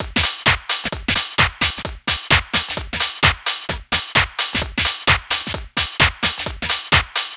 Downtown House
Drum Loops 130bpm